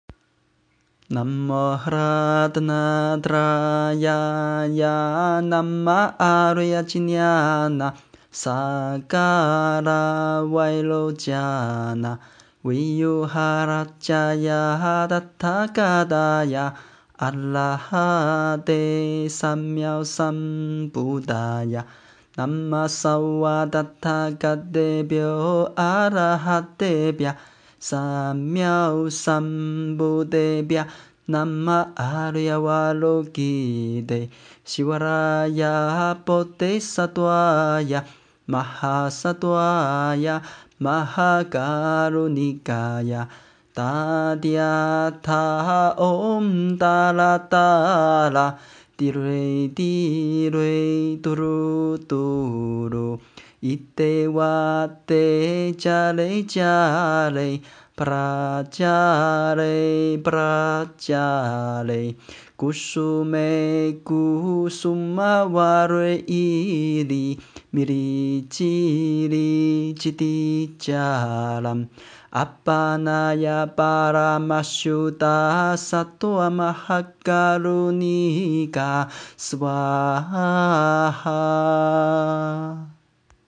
唱念梵音十一面观音神咒